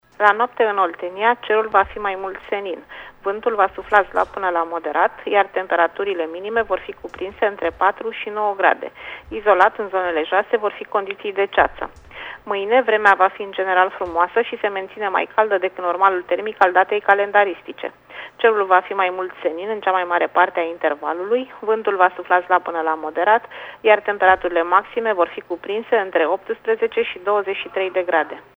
Prognoza meteo 13/14 octombrie (audio)